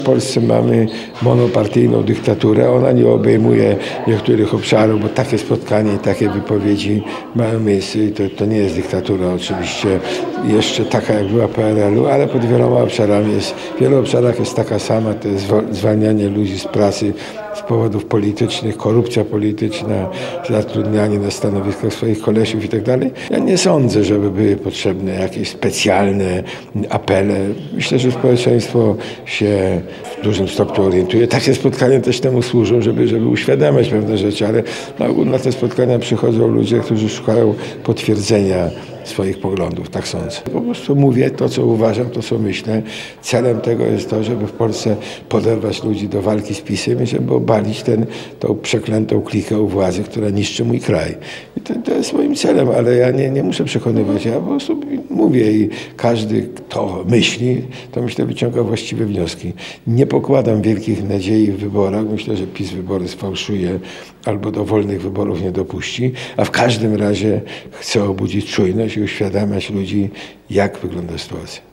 Poseł porównywał obecną sytuację w kraju, do tej w okresie PRL-u. Rządy Prawa i Sprawiedliwości nazwał monopartyjną dyktaturą. – Celem spotkania jest poderwanie ludzi do walki z PiSem – powiedział Niesiołowski podczas spotkania z dziennikarzami.